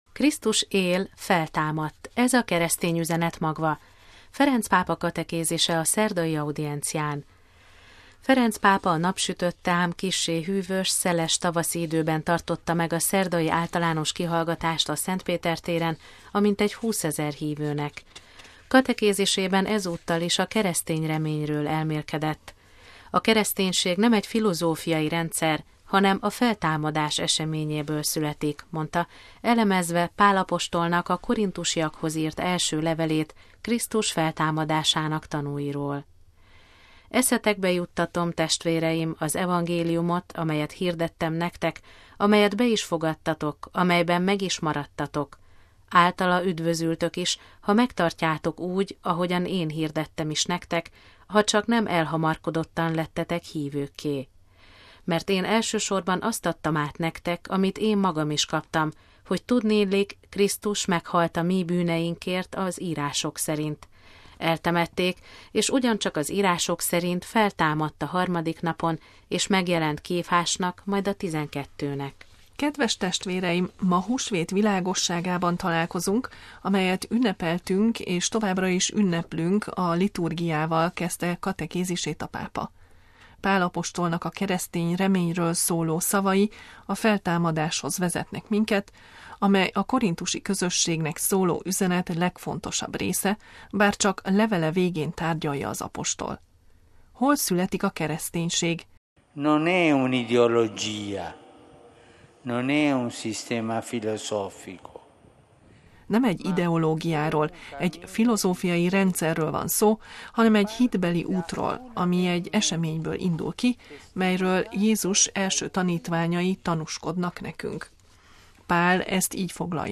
Krisztus él, feltámadt: ez a keresztény üzenet magva. Ferenc pápa katekézise a szerdai audiencián
Ferenc pápa a napsütötte, ám kissé hűvös, szeles tavaszi időben tartotta meg a szerdai általános kihallgatást a Szent Péter-téren a mintegy 20 ezer hívőnek. Katekézisében ezúttal is a keresztény reményről elmélkedett.